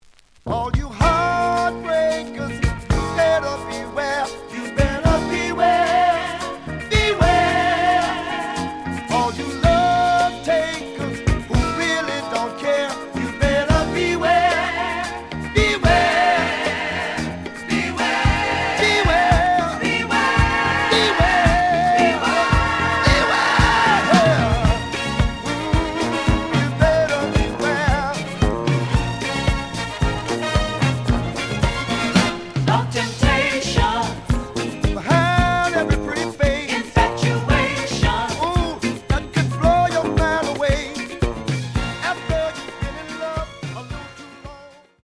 The audio sample is recorded from the actual item.
●Format: 7 inch
●Genre: Disco